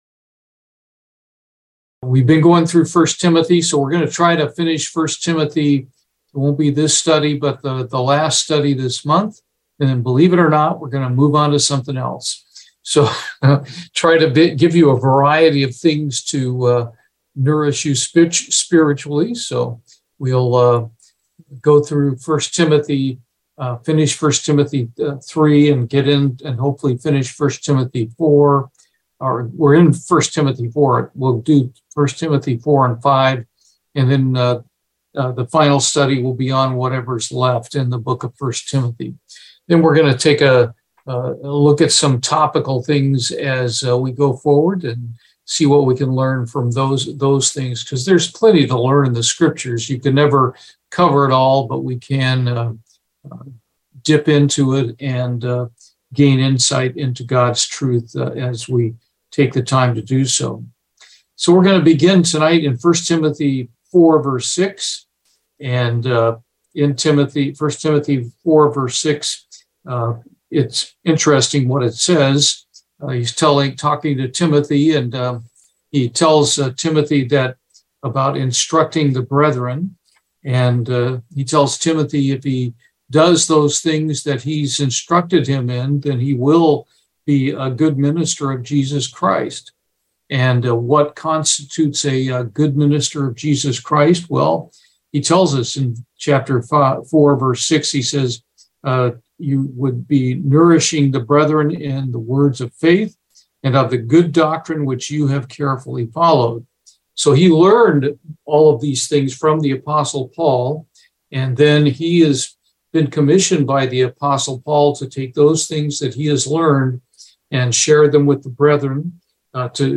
Bible Study, I Timothy 4:6 - 5:25
Given in Houston, TX